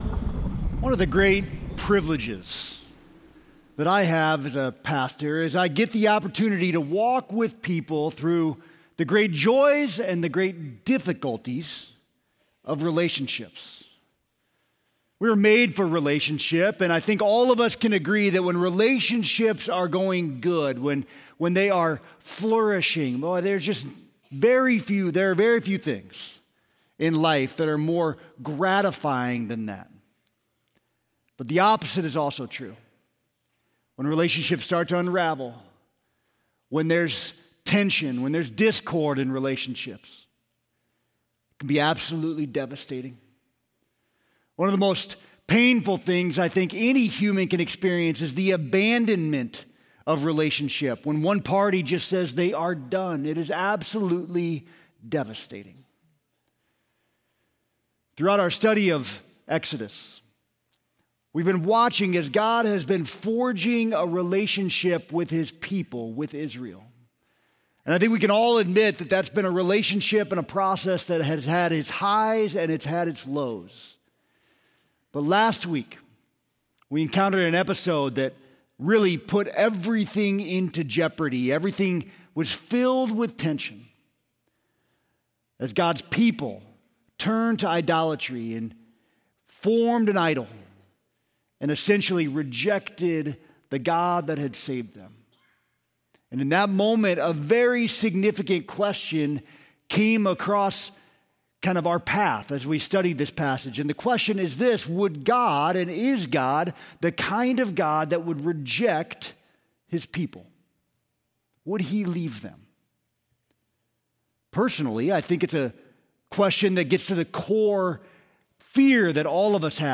Sermon - Lincoln Berean